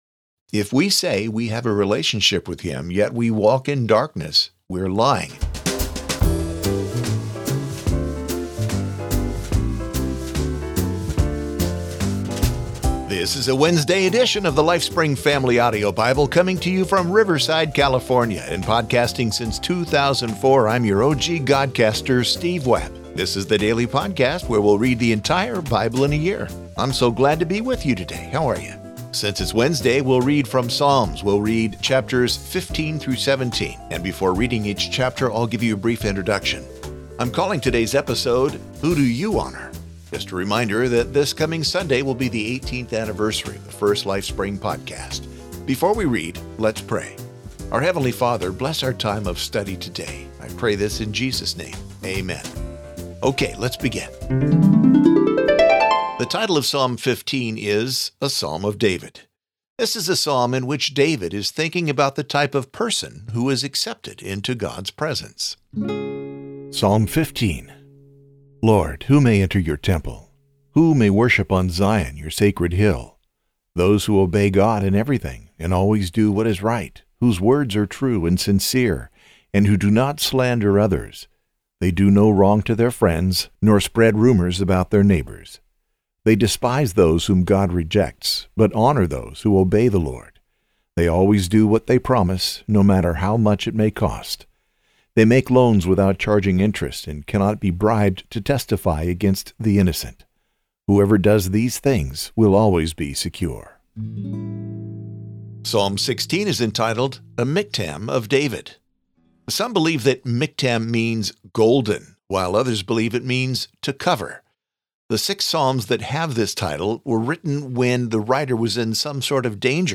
Each episode features a reading, followed by a short commentary.